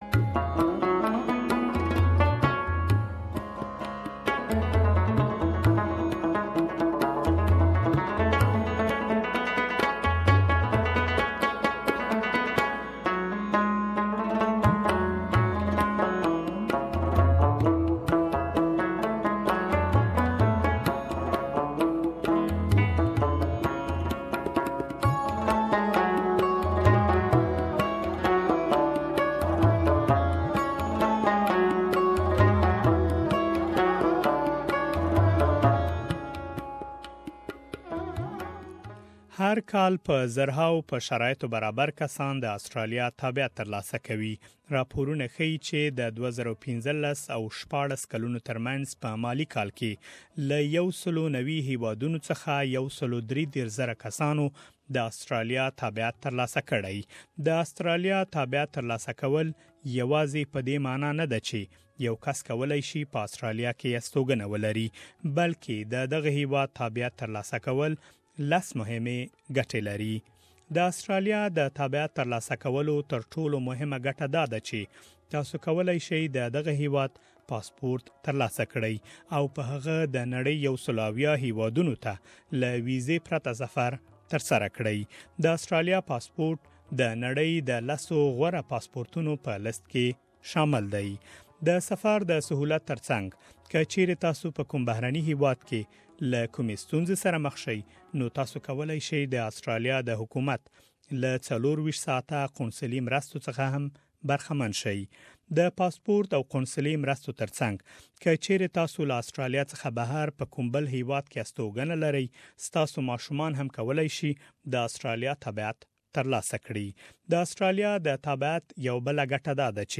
پدې اړه بشپړ رپوت دلته اوریدلی شئ